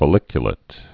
(fə-lĭkyə-lĭt) also fol·lic·u·lat·ed (-lātĭd)